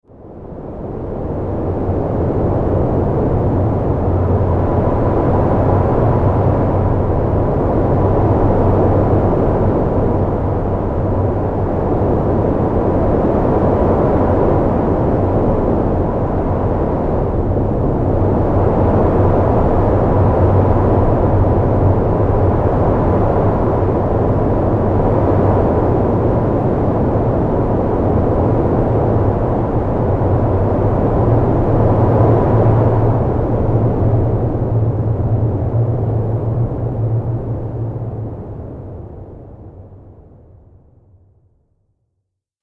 دانلود آهنگ باد 40 از افکت صوتی طبیعت و محیط
جلوه های صوتی
دانلود صدای باد 40 از ساعد نیوز با لینک مستقیم و کیفیت بالا